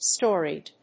アクセント・音節stó・reyed